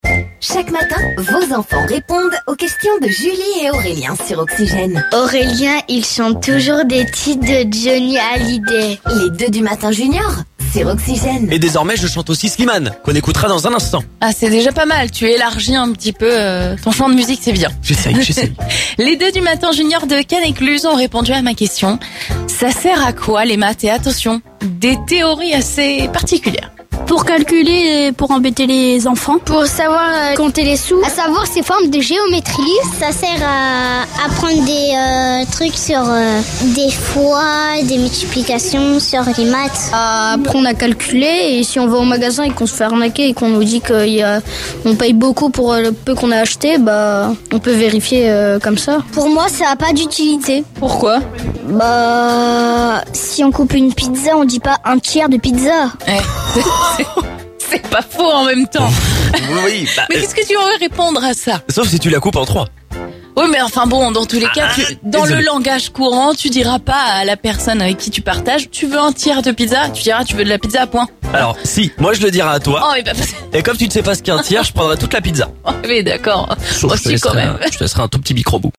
Tous les jours à 06h10, 07h10 et 09h10 retrouvez les 2 du Matin Junior sur Oxygène, la radio de la Seine et Marne !